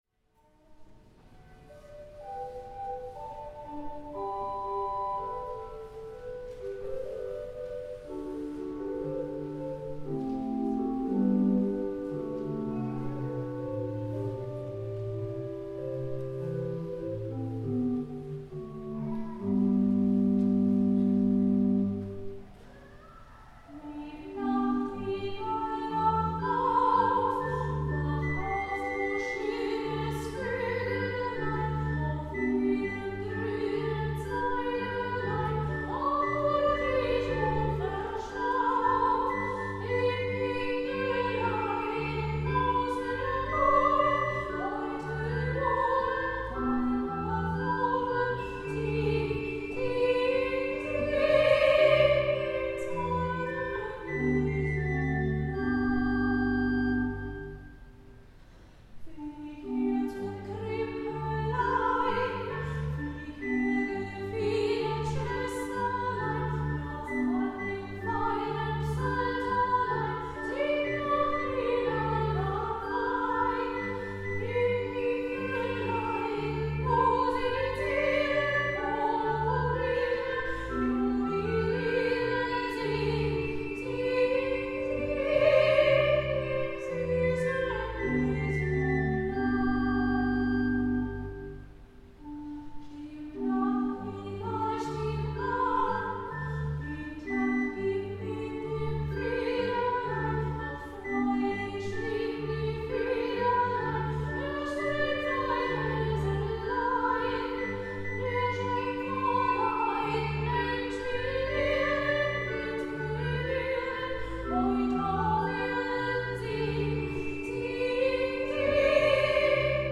Dezember, 2014, 10:00 Uhr, Martin-Luther-Kirche, Ulm
Singegottesdienst
Trad. Lied: Lieb Nachtigall wach auf